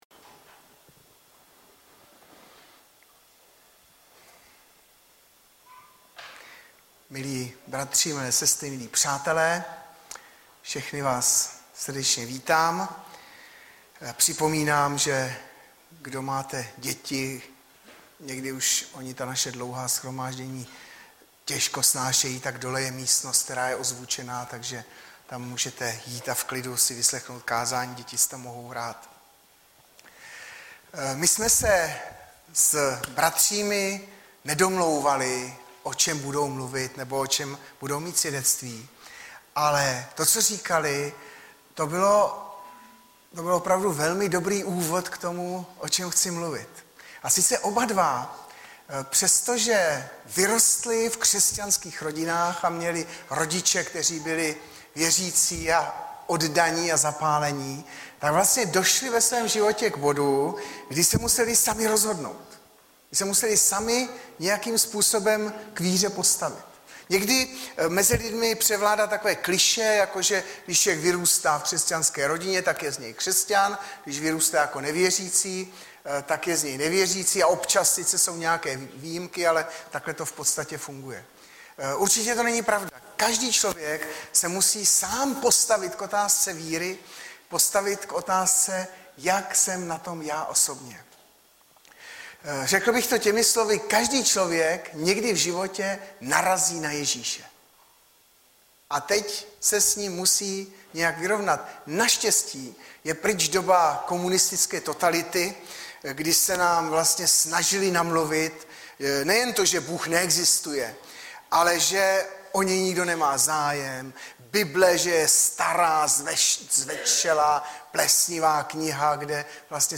Audiozáznam kázání si můžete také uložit do PC na tomto odkazu.